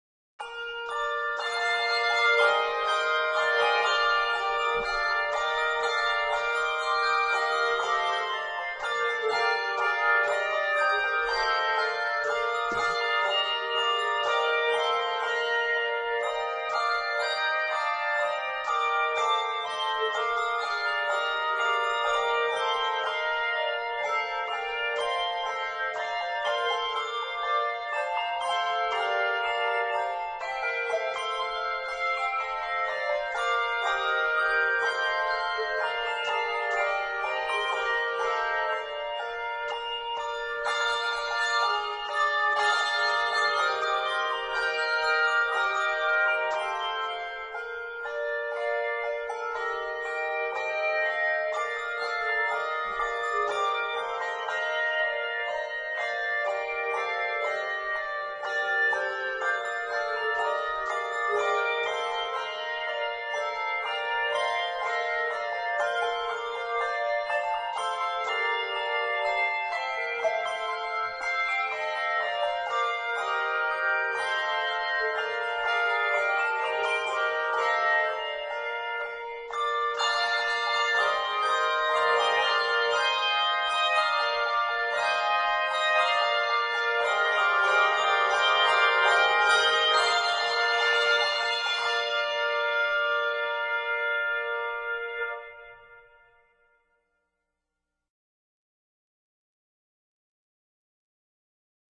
Octaves: 2